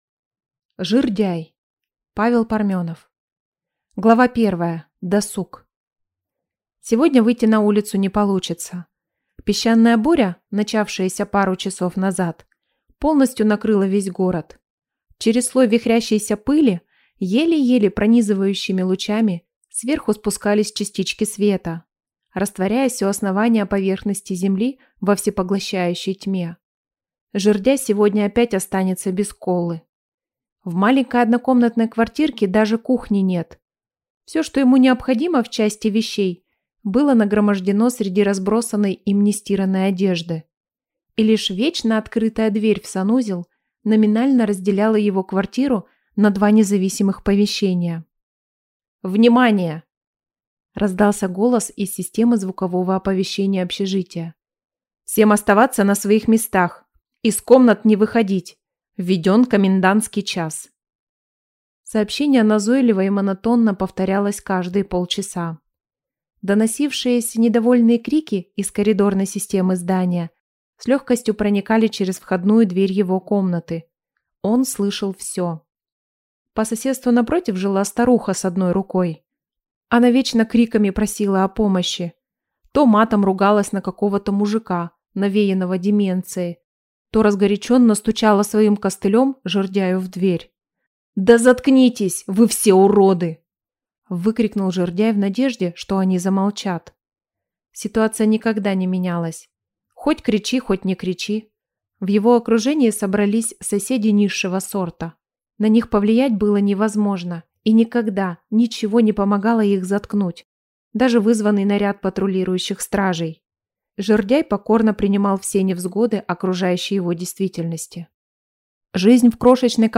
Аудиокнига Жирдяй | Библиотека аудиокниг